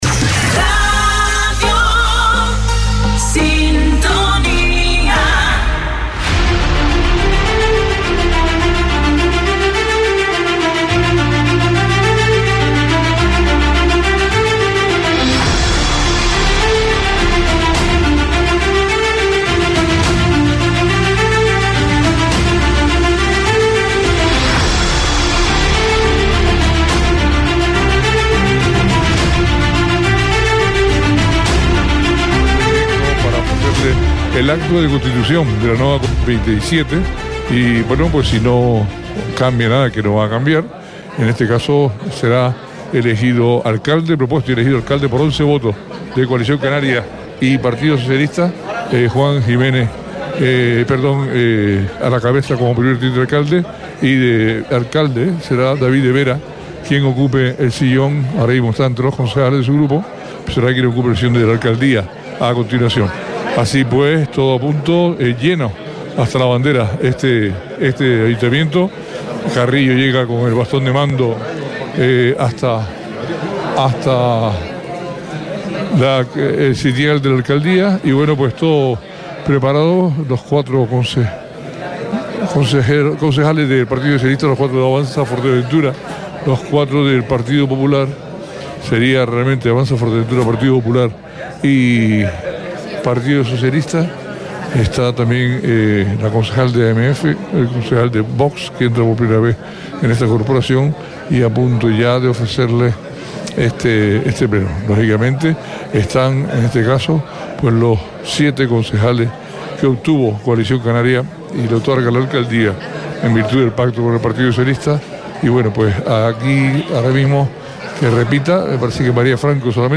Esta mañana se ha llevado a cabo el pleno de constitución de la corporación municipal del Ayuntamiento de Puerto del Rosario. David de Vera ha sido elegido, por 11 votos de Coalición Canaria y PSOE, como alcalde del municipio.
Entrevistas